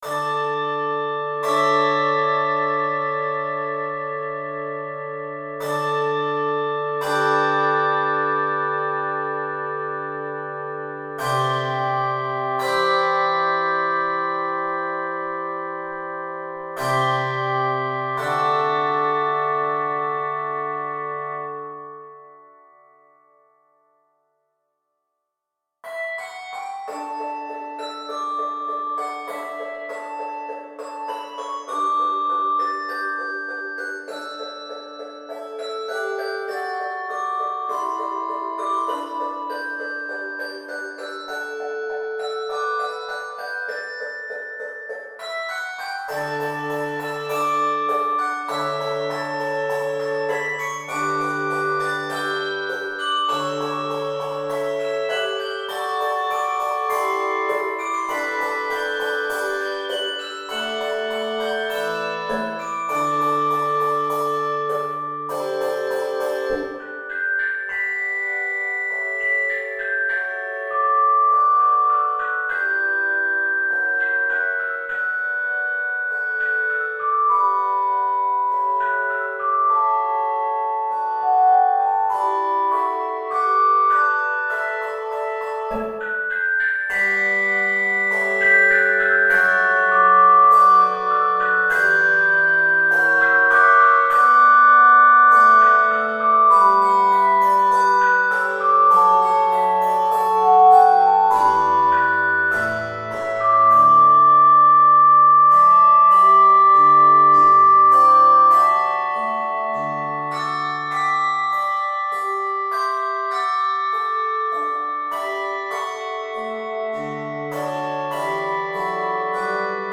Key of D Major.